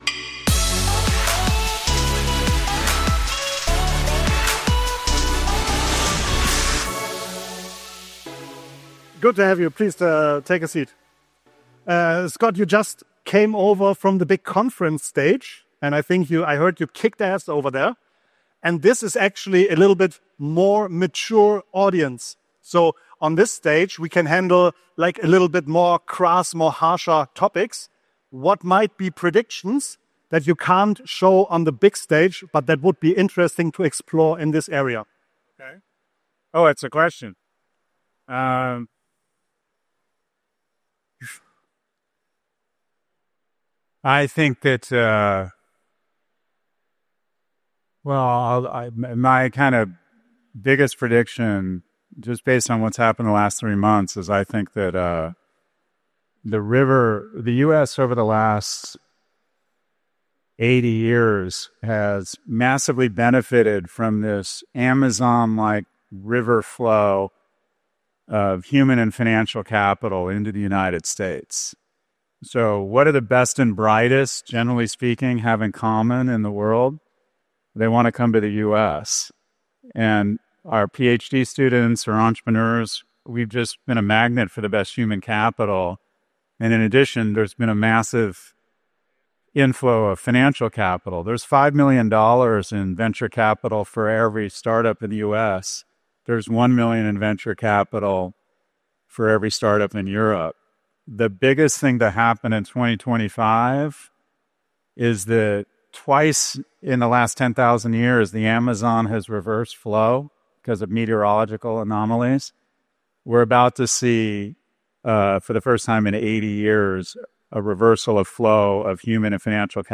Jeden Montag erwarten dich spannende Einblicke rund um das Thema Digitalisierung: echte Erfolgsgeschichten, Interviews mit Expertinnen und Vorbildern der digitalen Wirtschaft, Best Practices, konkrete Ansätze für dein Business und aktuelle News und Trends.